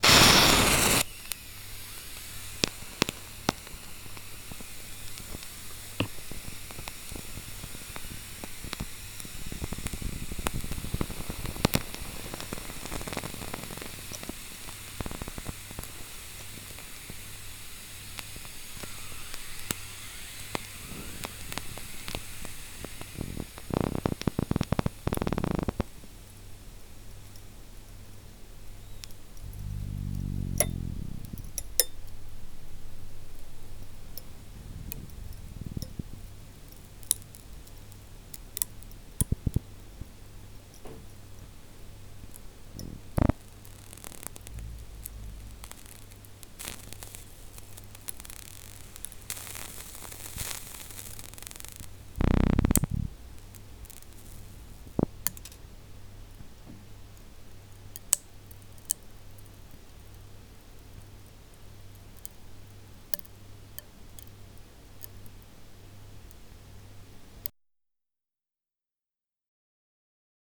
collectif field recording